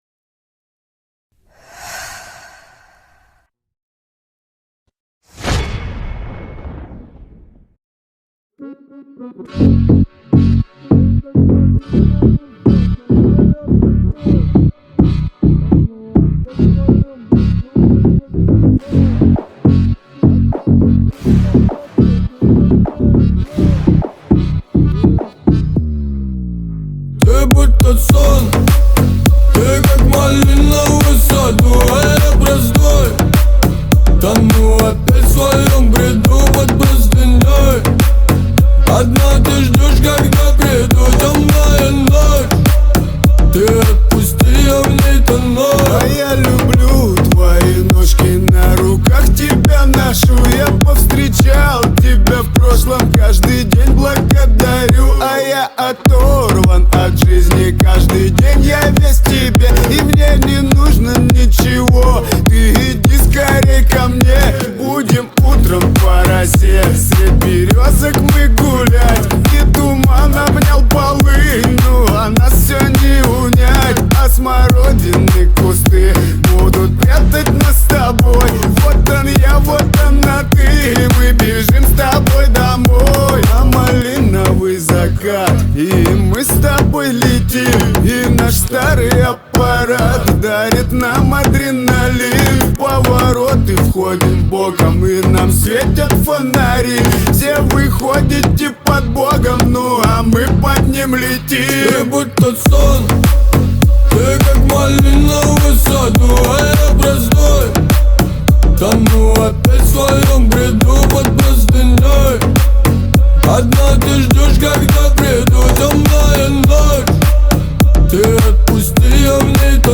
Русские поп песни, Поп музыка